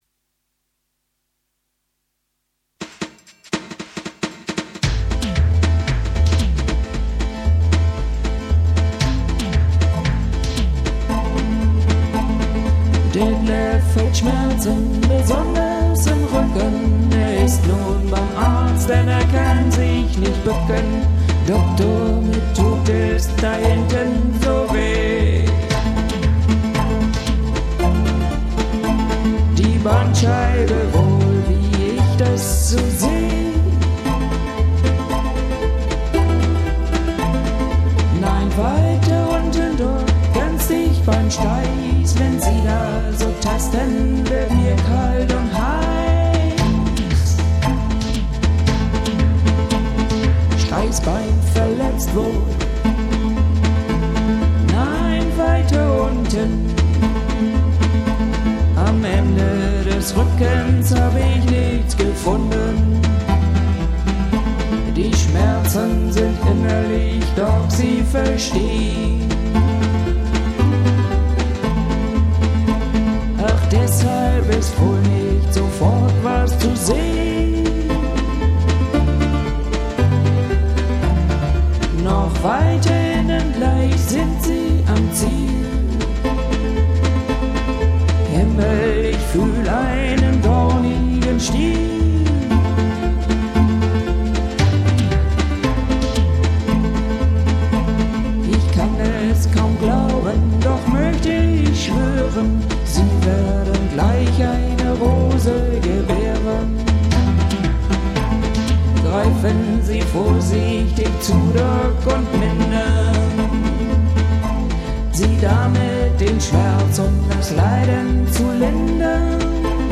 with artificial choir
Cajun Pop.